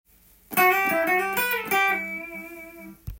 混ぜたギターフレーズ集
AミクソリディアンスケールにAマイナーペンタトニックスケールを混ぜた
おしゃれなフレーズ